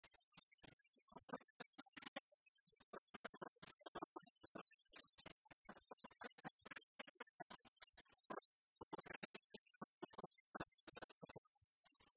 Chant de conscrits
Chants brefs - Conscription
Pièce musicale inédite